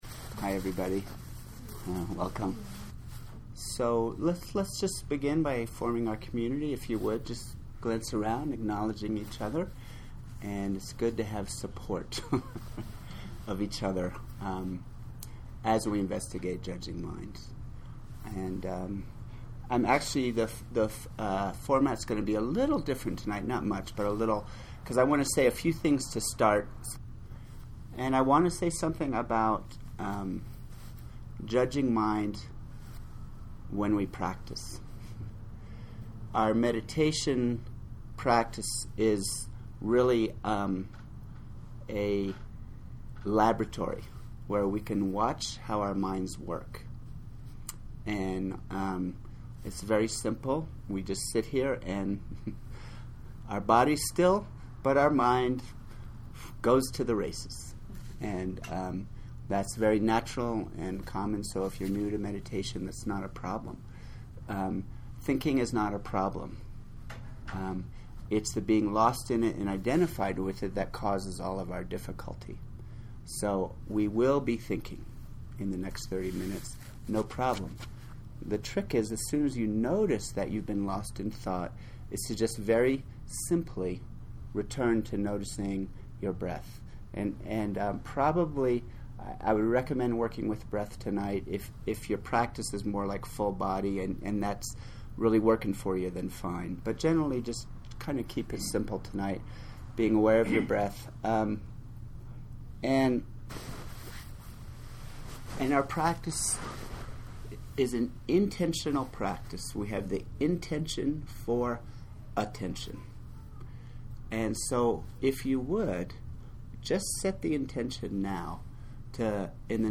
Transforming Judgmental Mind first class.mp3